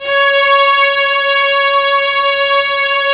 Index of /90_sSampleCDs/Roland LCDP13 String Sections/STR_Violas II/STR_Vas4 Amb f